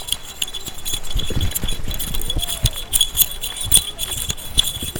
달팽이종소리.mp3